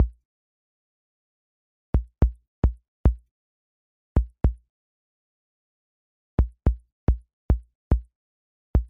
kick.ogg